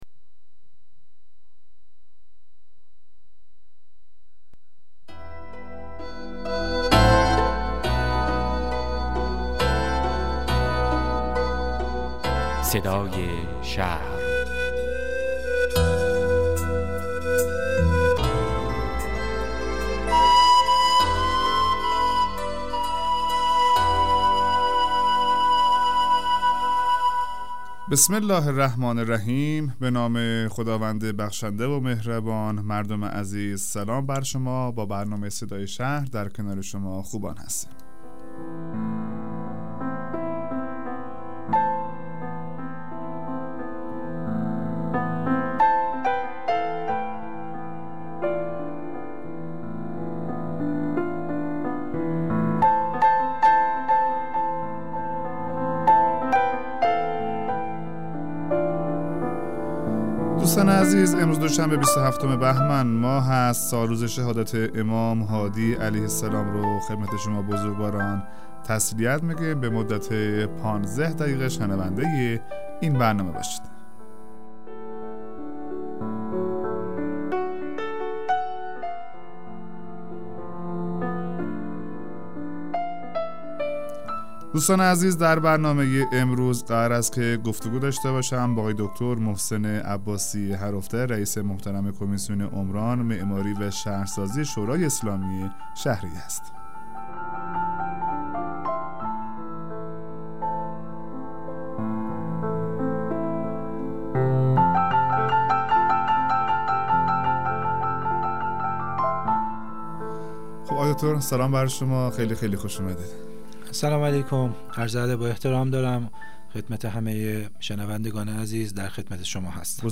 مصاحبه رادیویی برنامه صدای شهر با دکتر عباسی رییس کمیسیون عمران شورای اسلامی شهر یزد